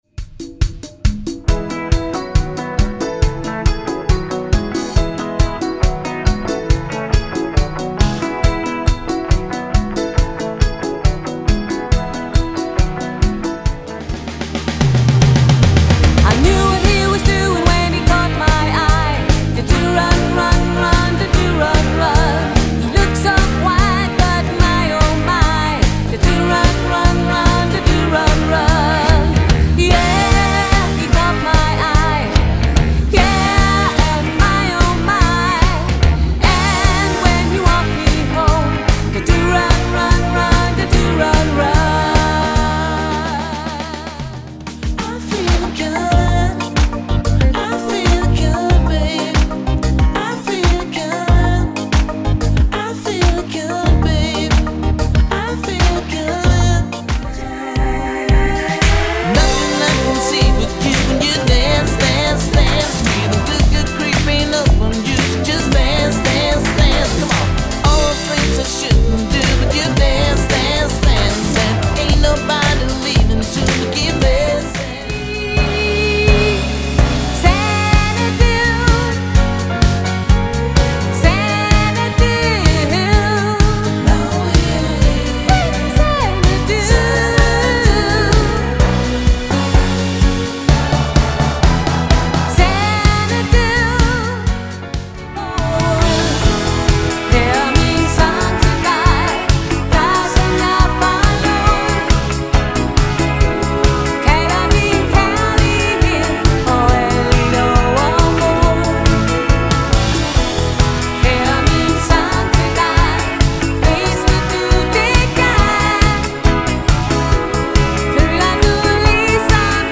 • Coverband
• Duo eller trio